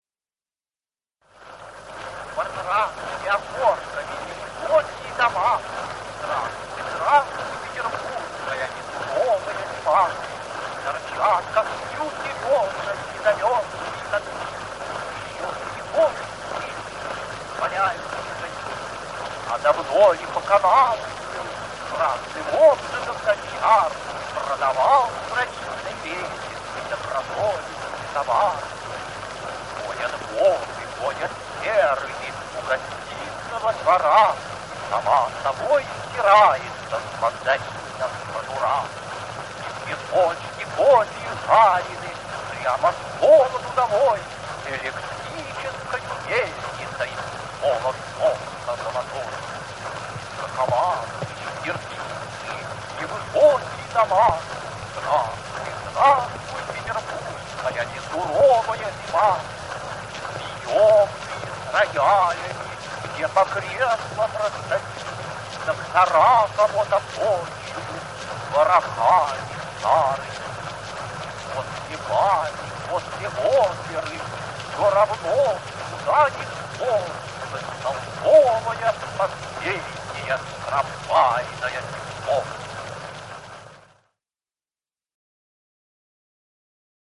Mandelshtam-Vy-s-kvadratnymi-okoshkami-nevysokie-doma-chitaet-avtor-stih-club-ru.mp3